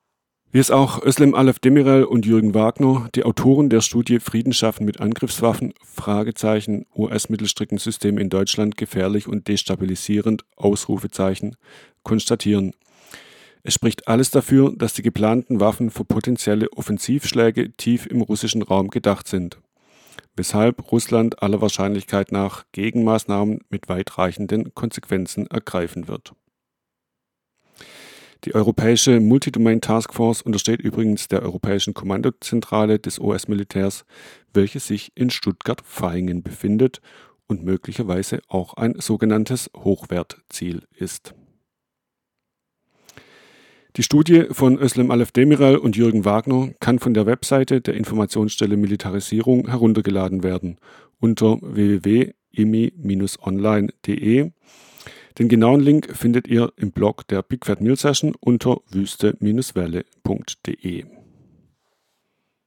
Text-to-Speech-Technologie von Piper und Thorsten-Voice